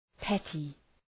{‘petı}